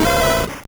Fichier:Cri 0058 OA.ogg — Poképédia
Cri de Caninos dans Pokémon Or et Argent.